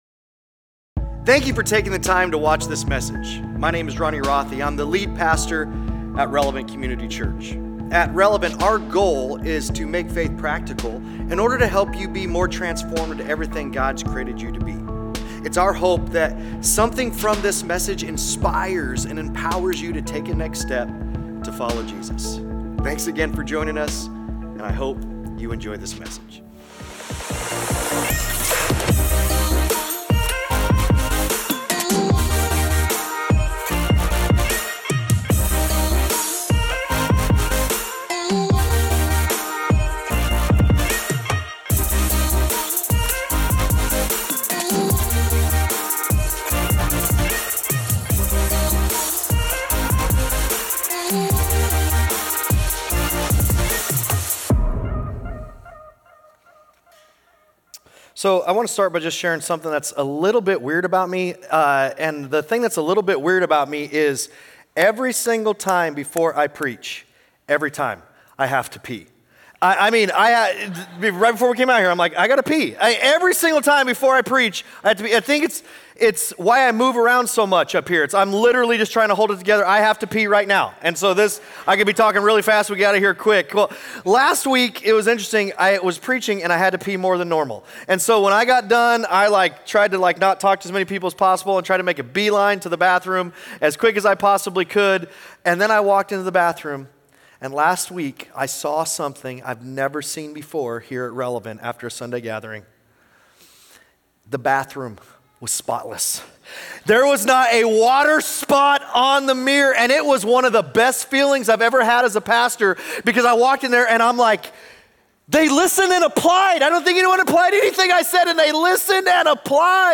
Sunday Sermons